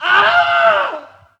Wilhelm Scream Alternative 2
Category 🗣 Voices
death fall falling famous killed legend legendary male sound effect free sound royalty free Voices